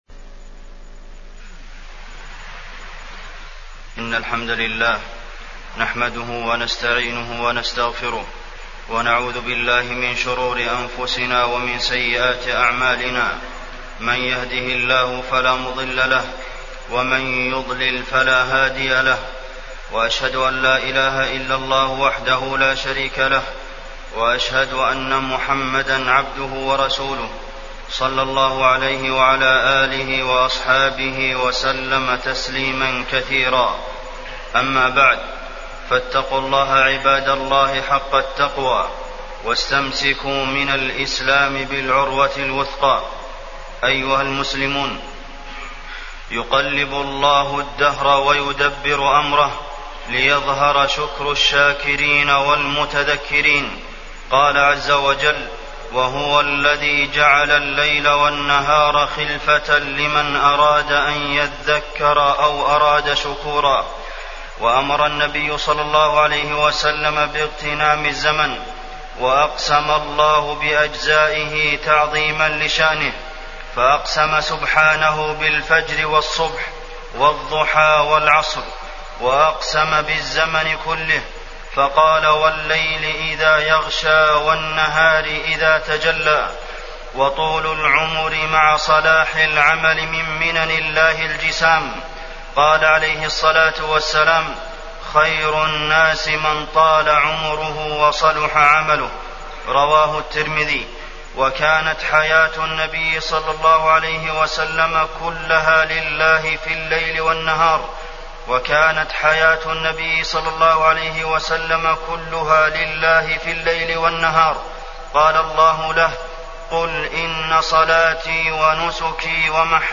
تاريخ النشر ٢٣ جمادى الآخرة ١٤٢٩ هـ المكان: المسجد النبوي الشيخ: فضيلة الشيخ د. عبدالمحسن بن محمد القاسم فضيلة الشيخ د. عبدالمحسن بن محمد القاسم اغتنام الوقت